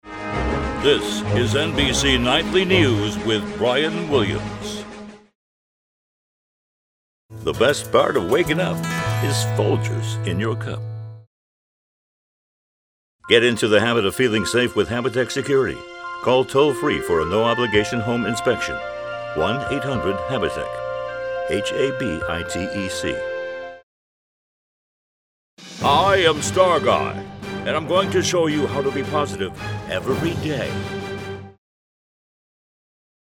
Voice Talent
Will do a quick sample script [ a few lines] at N/C.Same day turnaround High quality custom made sound booth. We use the finest microphones and mixers.
Professional VOICE with recording studio
Short audio demo